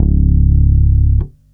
3-C#1.wav